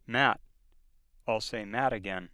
Length differences associated with voiced and voiceless final stop consonants
Spoken in an American voice